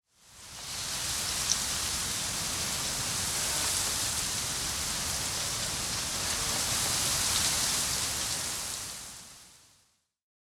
windtree_5.ogg